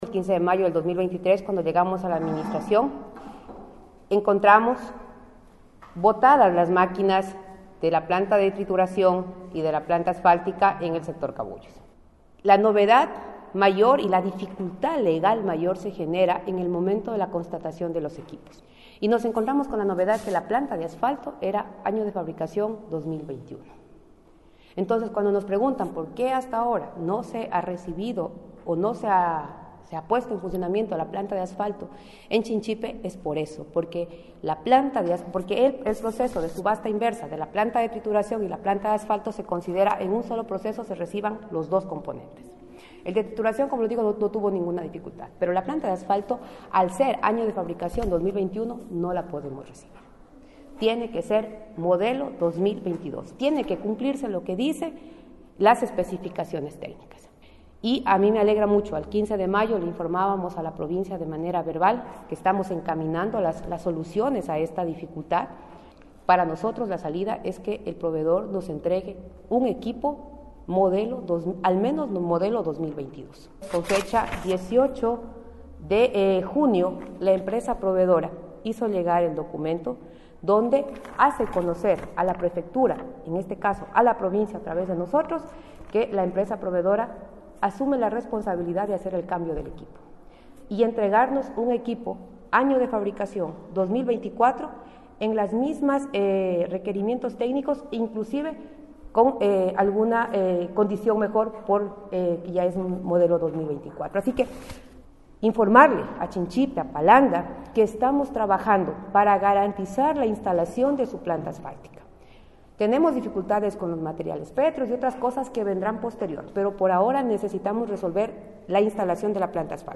La prefecta Karla Reátegui y el viceprefecto Víctor Sarango, en rueda de prensa informaron sobre el proceso en que se encuentra la entrega de la planta de asfalto situada en Cabuyos, del cantón Chinchipe, misma que al momento no se puede poner en operatividad por ser del año 2021 y no del año 2022 como constaba en el proceso de contratación al momento de su adquisición.
KARLA REÁTEGUI, PREFECTA
KARLA-REATEGUI-PREFECTA.mp3